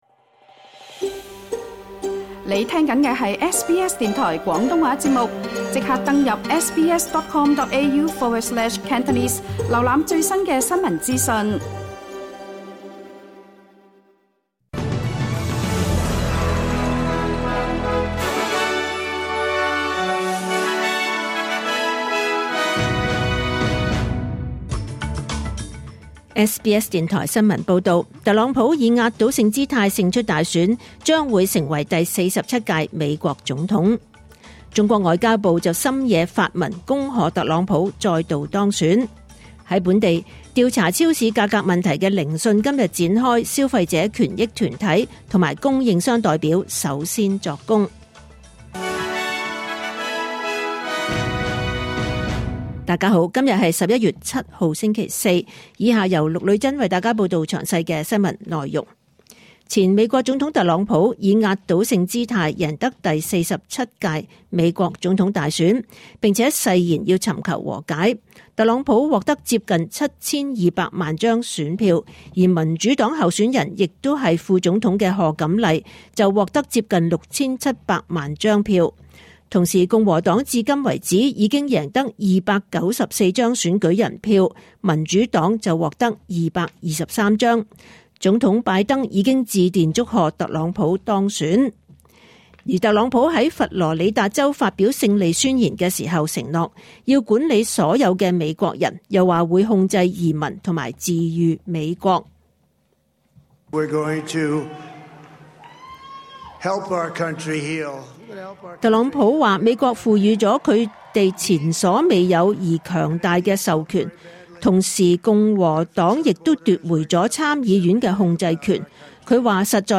2024 年 11 月 7 日 SBS 廣東話節目詳盡早晨新聞報道。